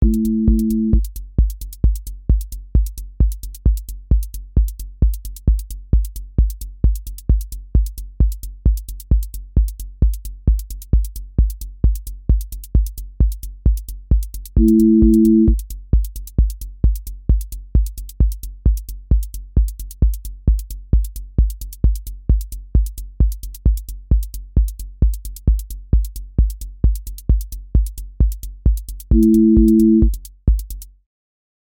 Polyrhythmic Tension Slow
QA Listening Test techno Template: techno_hypnosis
hypnotic techno pressure loop with warehouse percussion, low-mid drive, and evolving texture motion
• voice_kick_808
• voice_hat_rimshot
• voice_sub_pulse